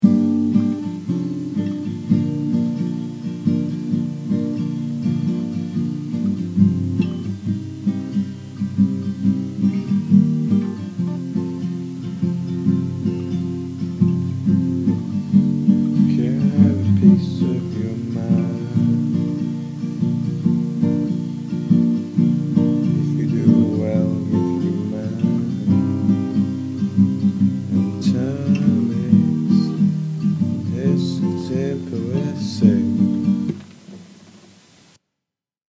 Novice Solving Issues with backgroud hiss
HI everyone, im just starting out recording, my setup comprises of a macbook with garageband, a subzero dynamic mic and an XLR to USB cable plugged into my computer. Ive started trying to record some stuff today and there is a horrible background hiss that ruins the sound (ill attach a sample)...